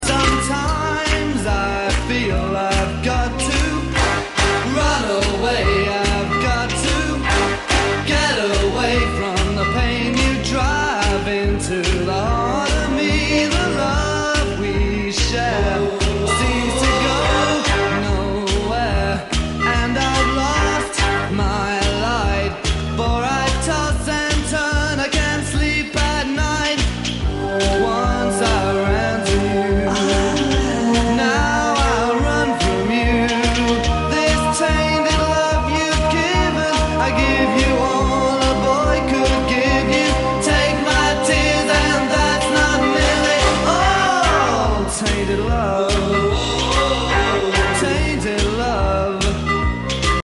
#80s